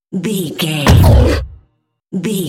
Dramatic monster hit
Sound Effects
heavy
intense
dark
aggressive
hits
the trailer effect